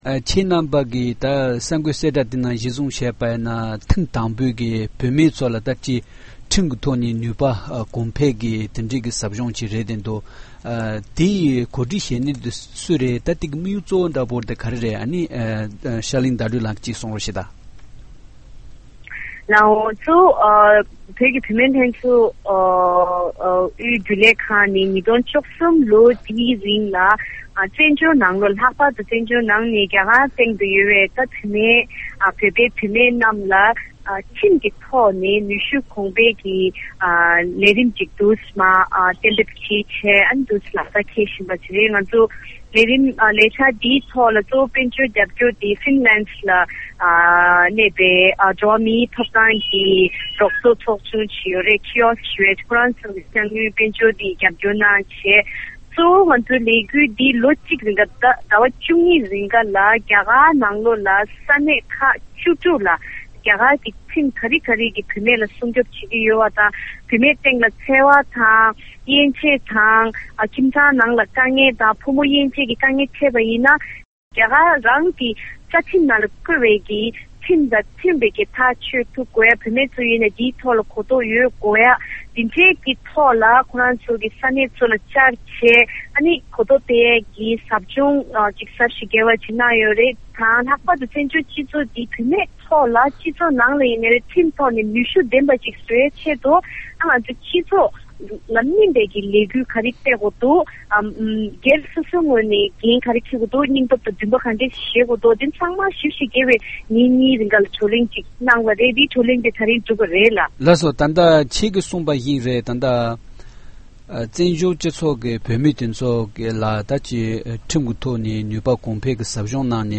བཅའ་འདྲི་བྱེད་པ་ཞིག་ཉན་རོགས་ཞུ༎